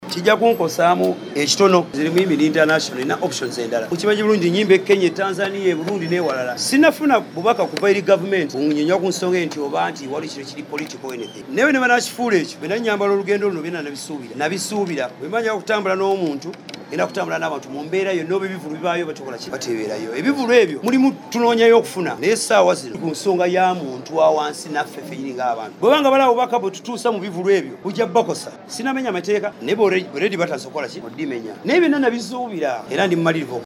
Mu kwogerako eri bannamawulire ku kitebe kya DP mu Kampala, Chameleone agamba nti abadde afuna ssente okuva mu bivvulu kyokka naye munnayuganda era naye anyigirizibwa ku mbeera embi eri mu ggwanga.